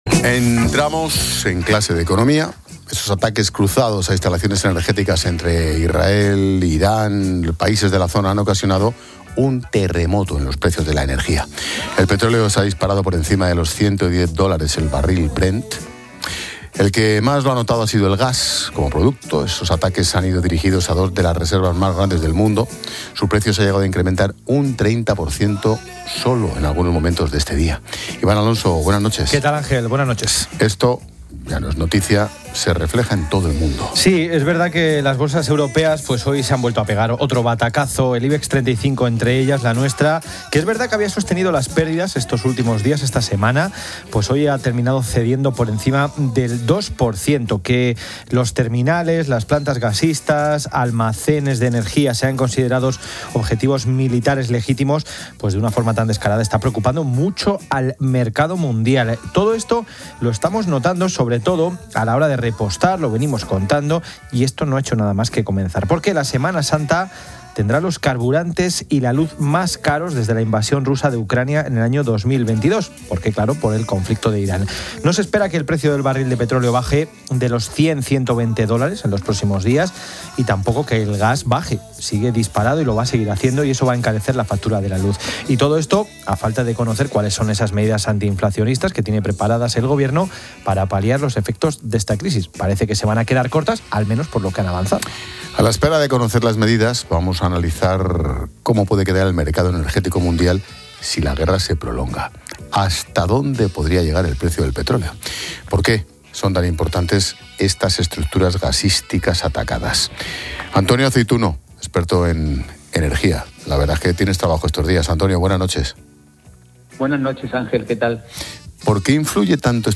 Expósito entrevista